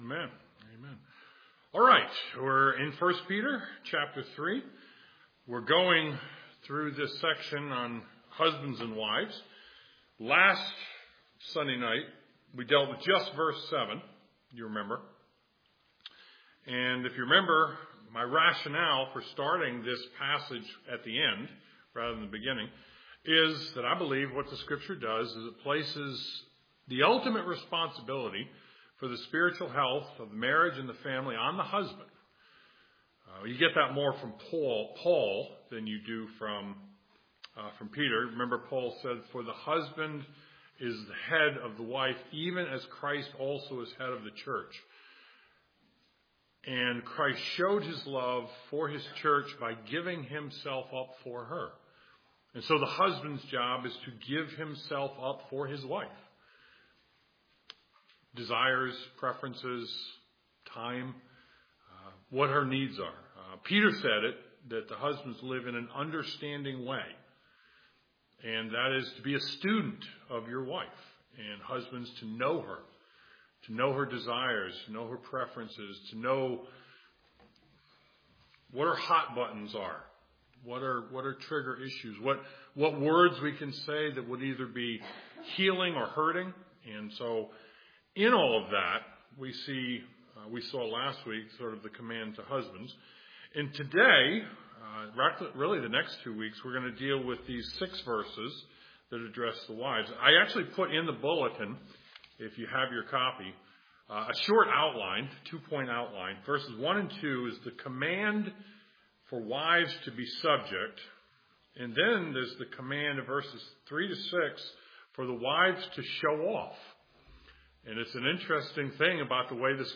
1 Peter 3:1-2 Service Type: Sunday Evening 1 Peter 3:1-2 Godly wifehood consists of godly self-restraint